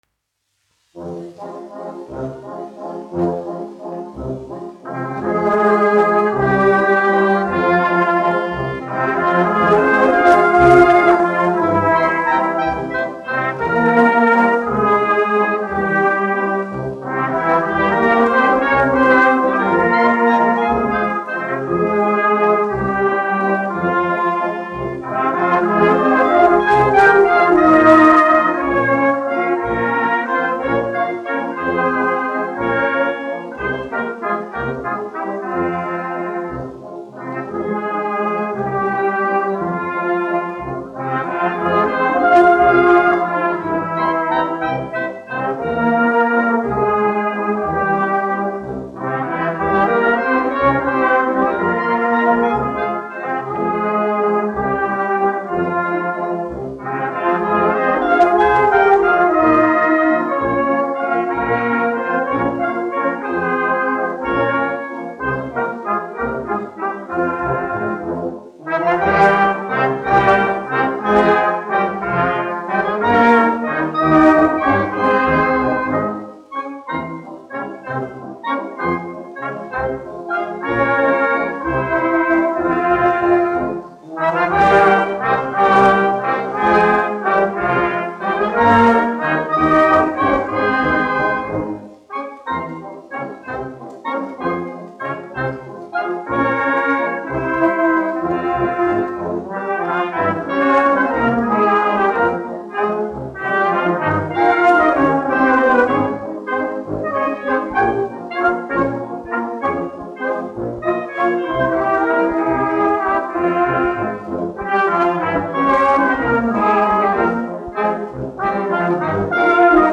1 skpl. : analogs, 78 apgr/min, mono ; 25 cm
Pūtēju orķestra mūzika
Skaņuplate